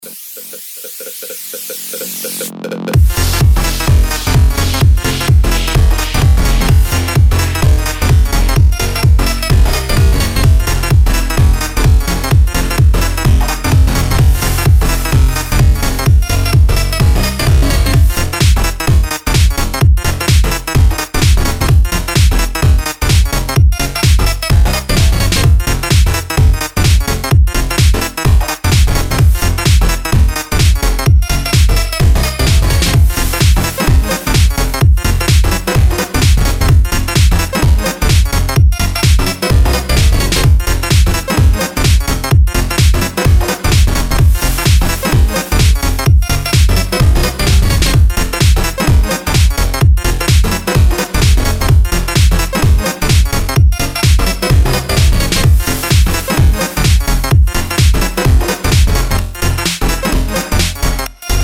• Качество: 192, Stereo
для любителей отличного Electro House